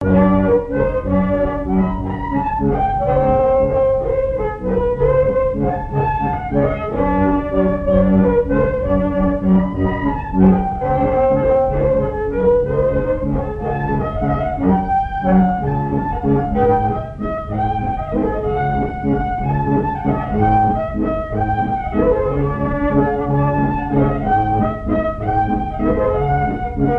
Vendée
danse : valse
Airs à danser aux violons
Pièce musicale inédite